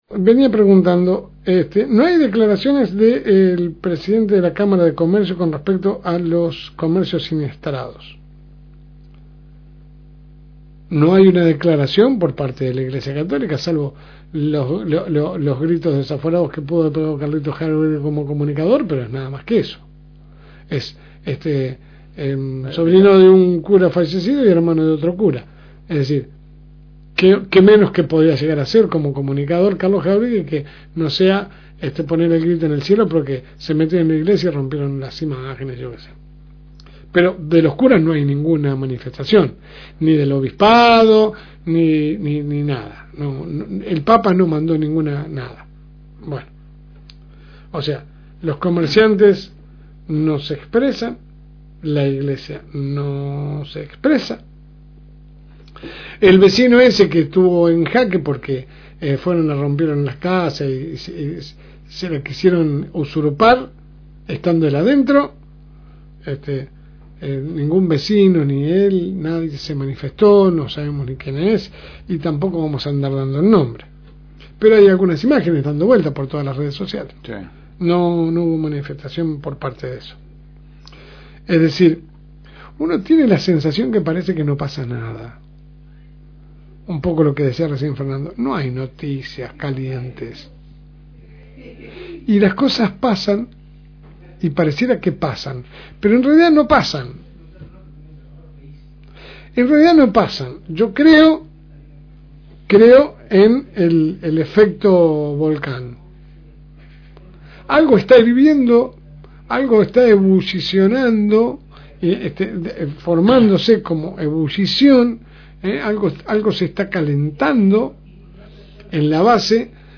AUDIO – Editorial de LSM – FM Reencuentro